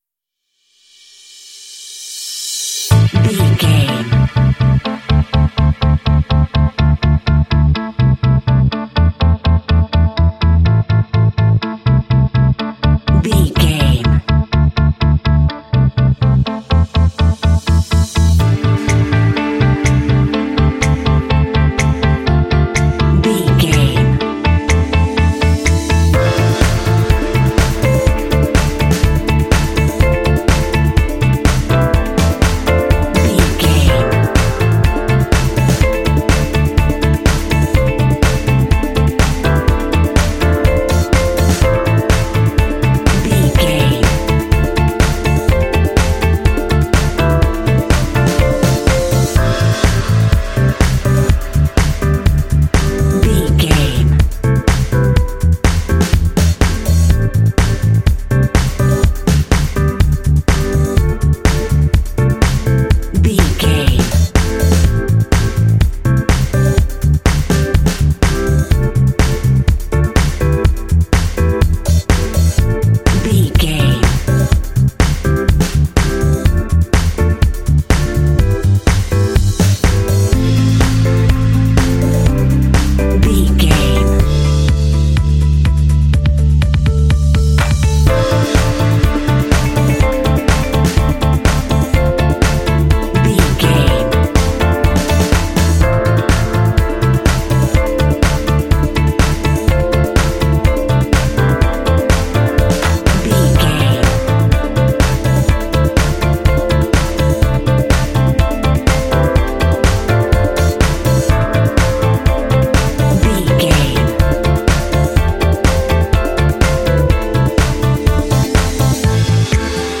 Aeolian/Minor
funky
smooth
groovy
driving
synthesiser
drums
strings
piano
electric guitar
bass guitar
electric piano
indie
alternative rock
contemporary underscore